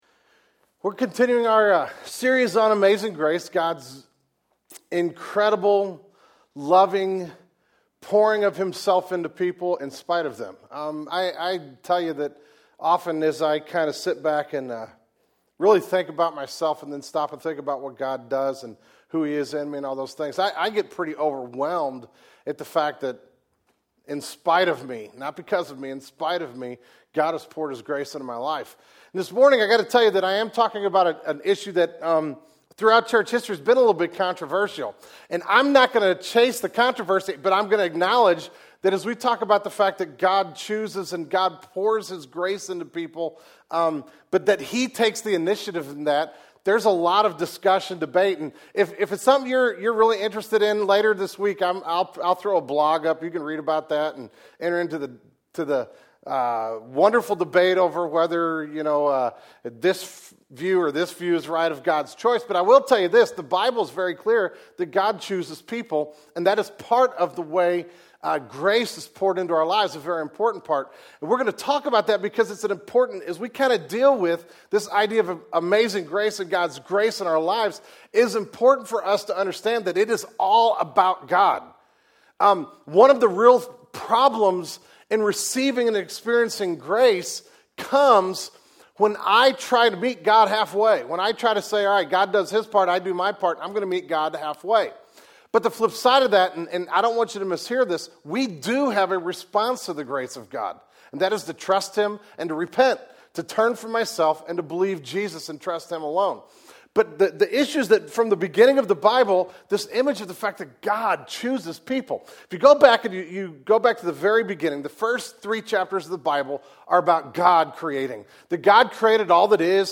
The November 2007 Sermon Audio archive of Genesis Church.